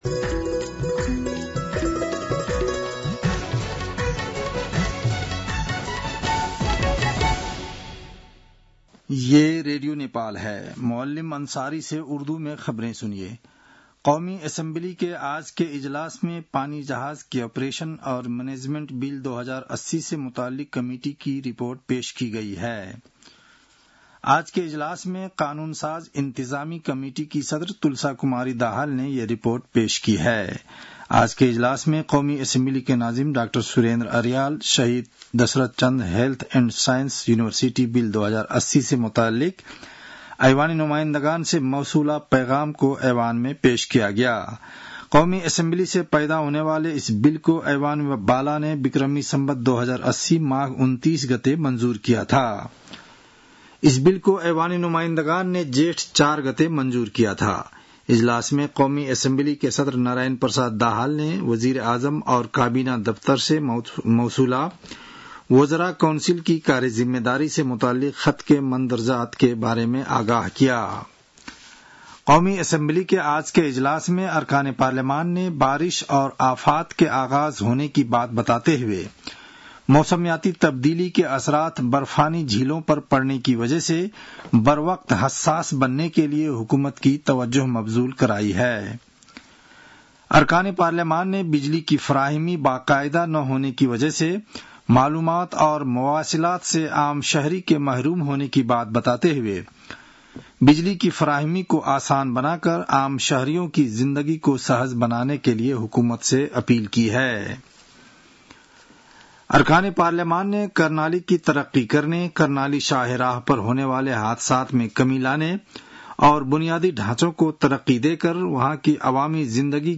An online outlet of Nepal's national radio broadcaster
उर्दु भाषामा समाचार : ७ जेठ , २०८२